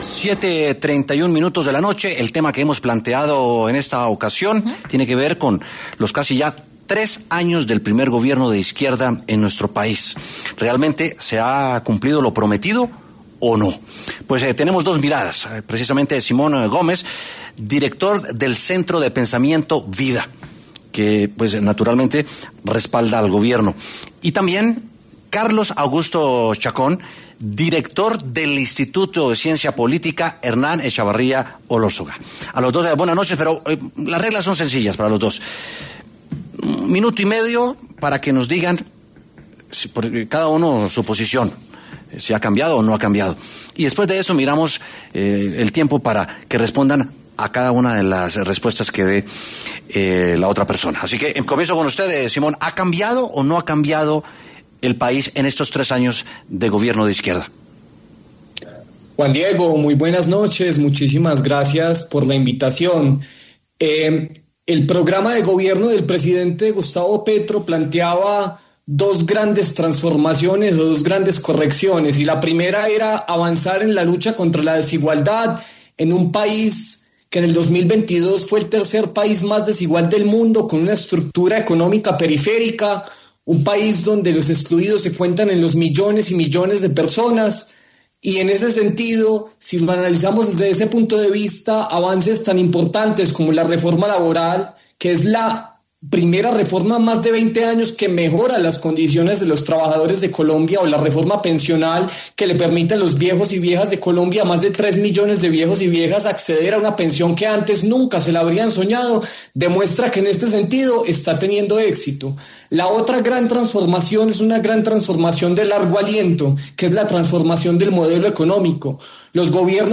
W Sin Carreta habló con dos expertos, cada uno con una postura diferente frente al Gobierno. ¿Cuáles son los logros y las debilidades del Gobierno de Gustavo Petro?